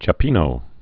(chə-pēnō)